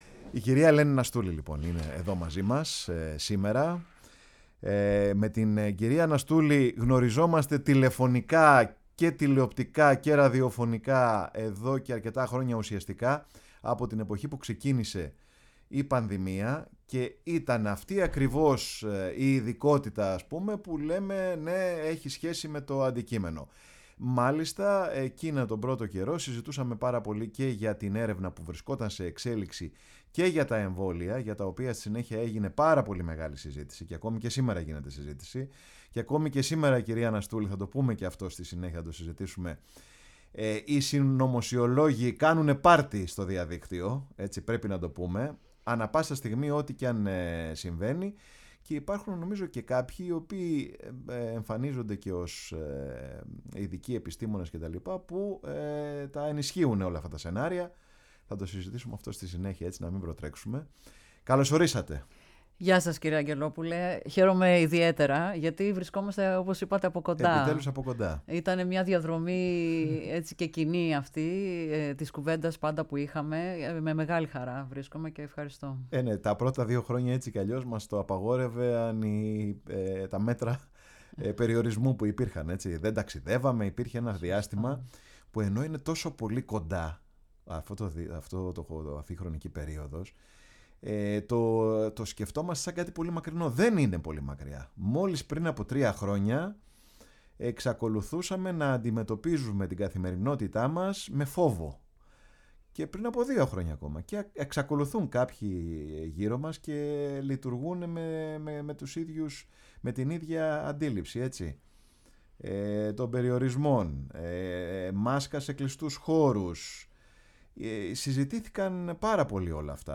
καλεσμένη στο στούντιο